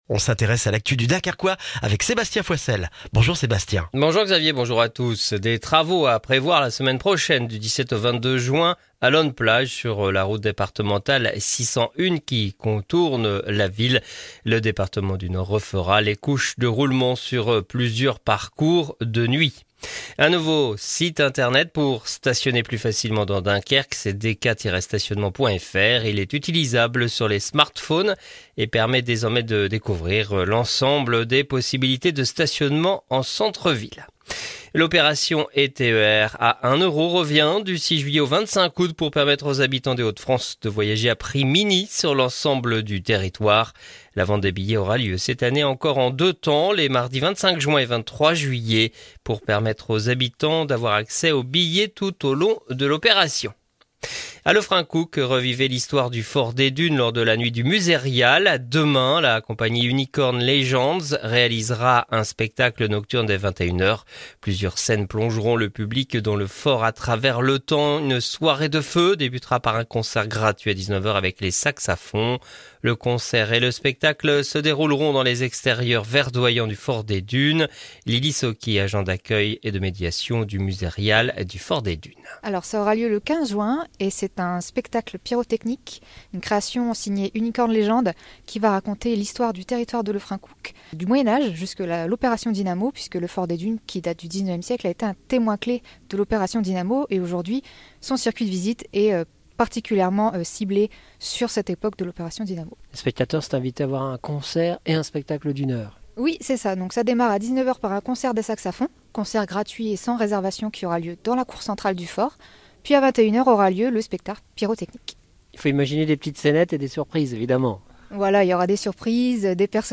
LE JOURNAL DU vendredi 14 JUIN dans le dunkerquois